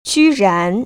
[jūrán] 쥐란  ▶